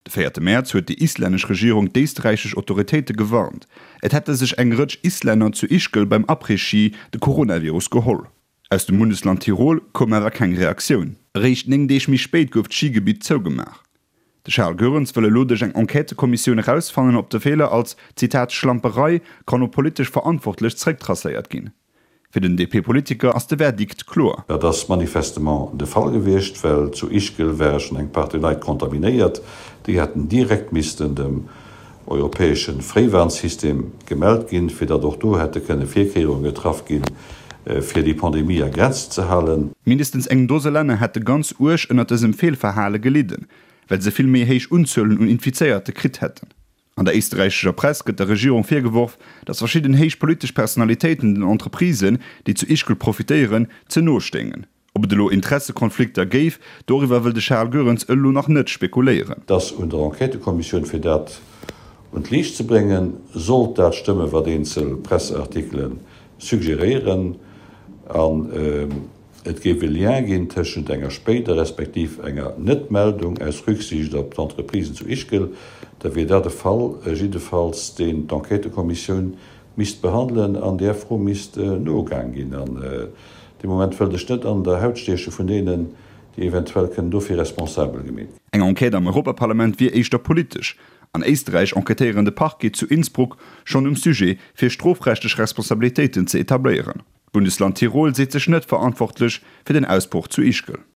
Dëse Reportage gouf fir RTL Radio Lëtzebuerg produzéiert, a gouf den 12. Juni iwwedroen.